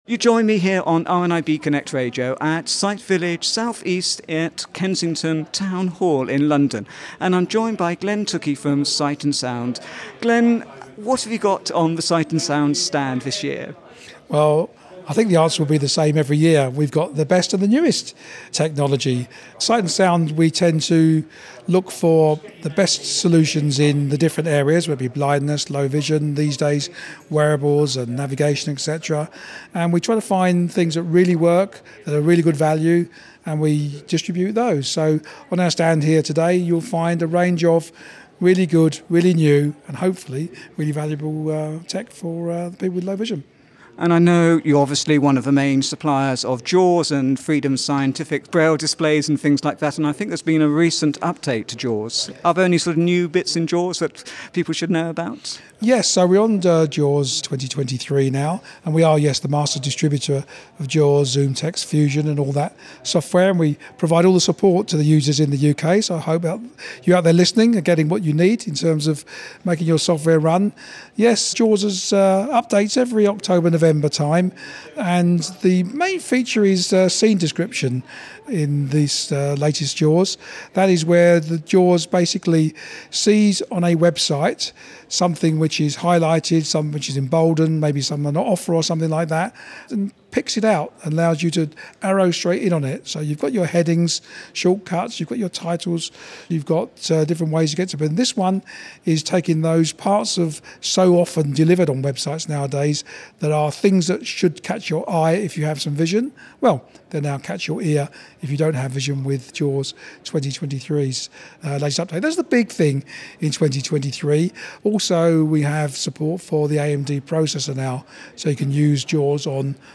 The annual exhibition of technology and services for blind and partially sighted people, Sight Village South East was back at Kensington Town Hall in London on Tuesday 8 November 2022